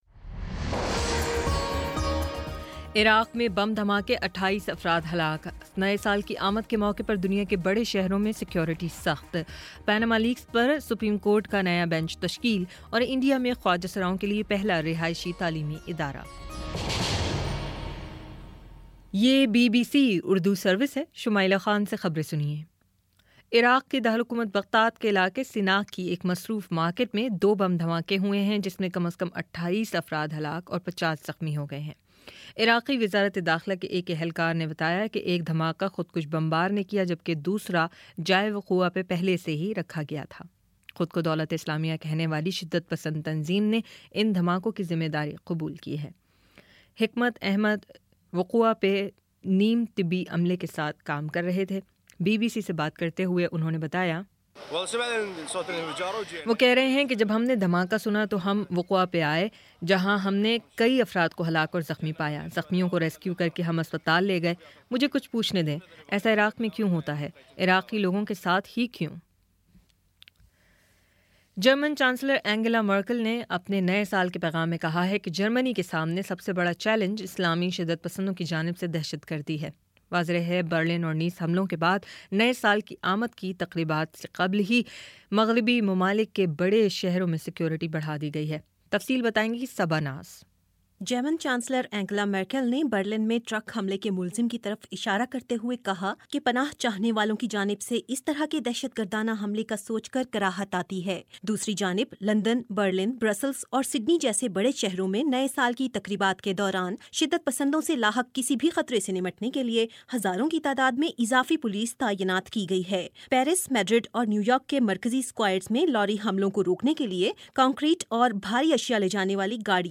دسمبر 31 : شام چھ بجے کا نیوز بُلیٹن